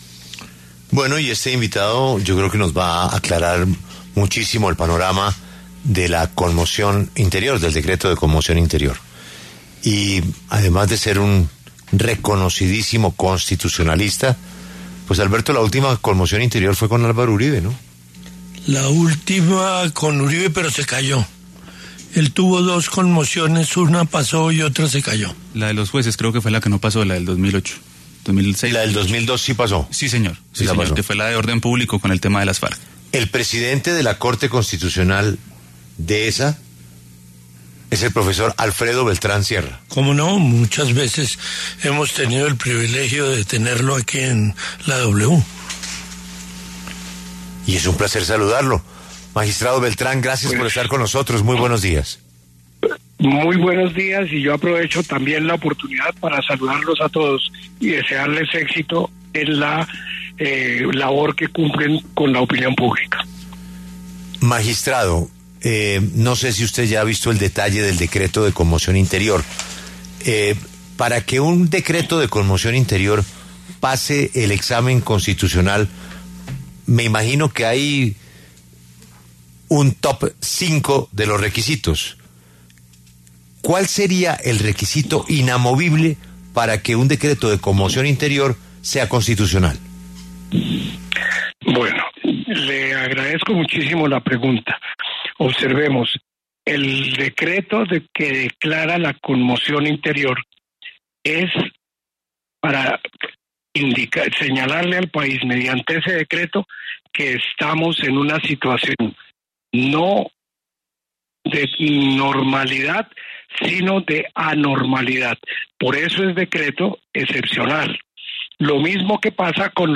El expresidente de la Corte Constitucional, Alfredo Beltrán, pasó por los micrófonos de La W para explicar las atribuciones que podría tener el ejecutivo en el marco del estado de conmoción que decretó sobre la situación en Norte de Santander.